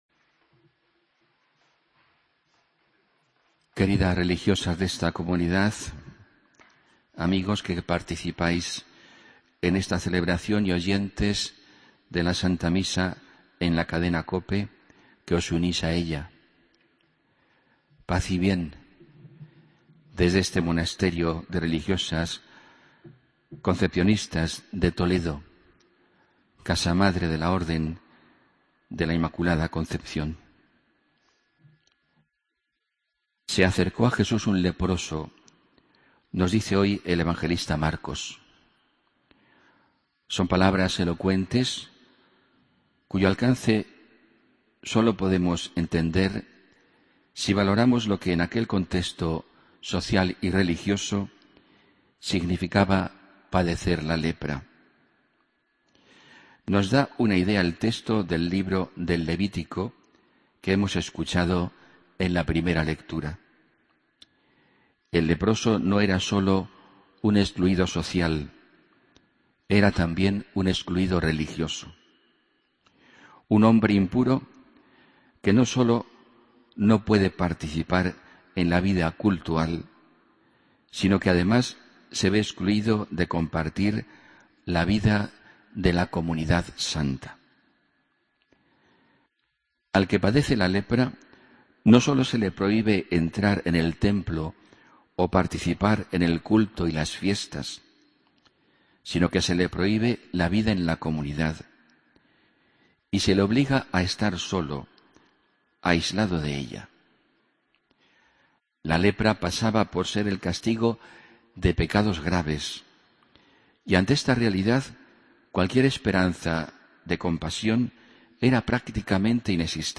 Homilía del 15 de Febrero de 2015